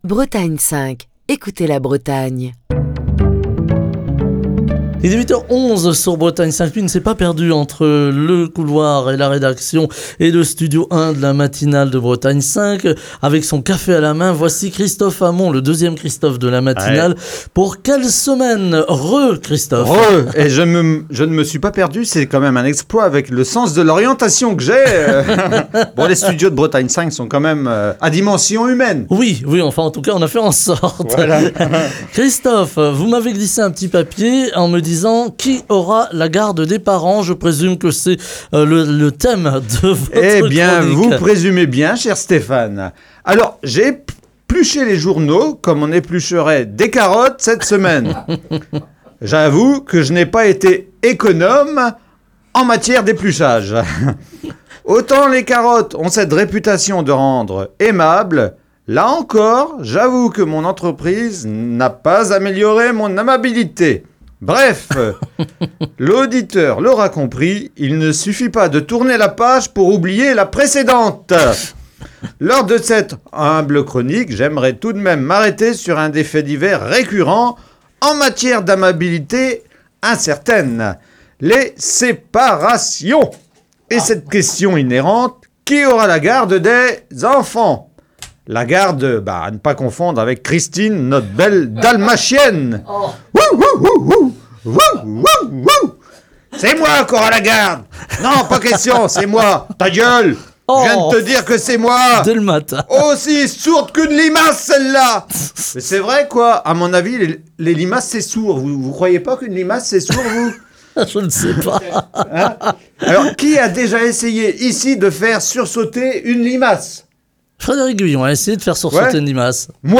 Chronique du 4 octobre 2024.